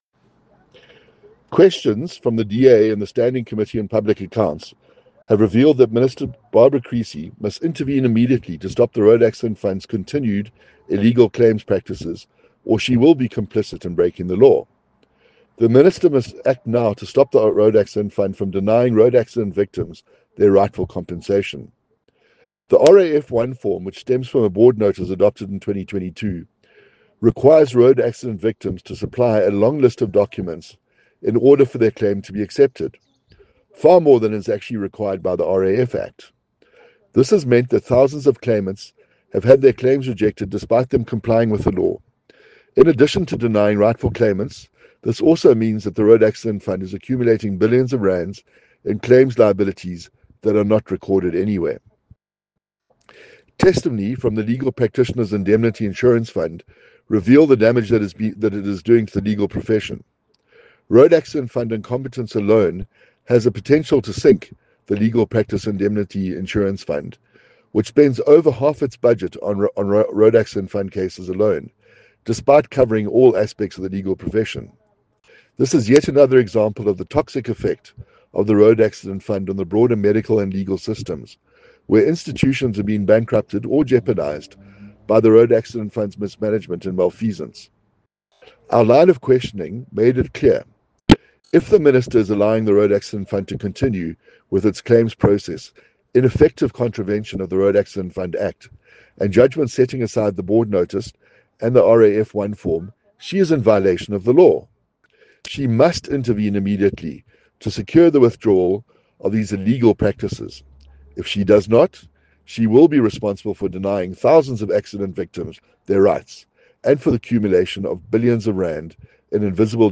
soundbite by Patrick Atkinson MP